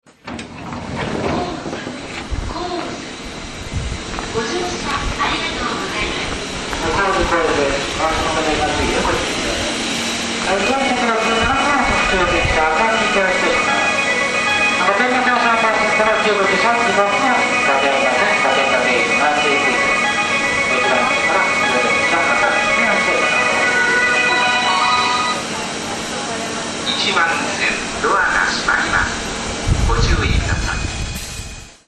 発車メロディーは東海道線標準のものです。